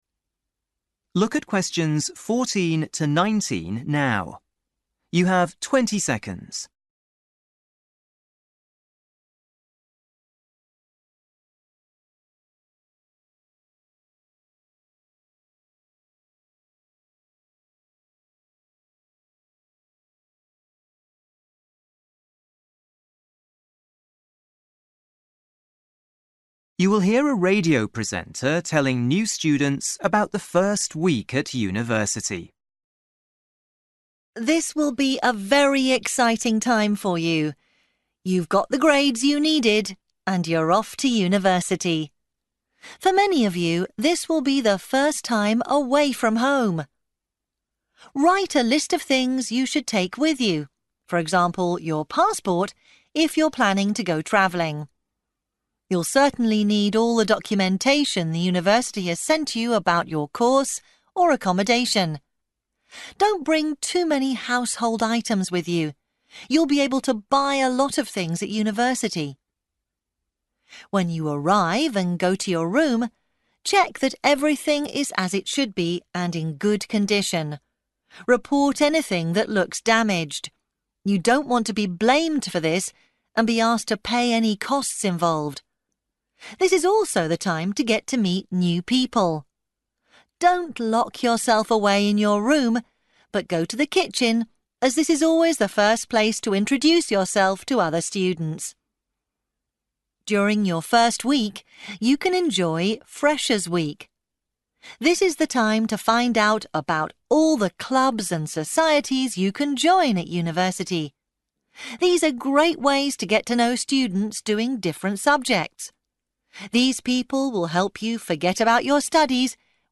You will hear a radio presenter telling new students about the first week at university.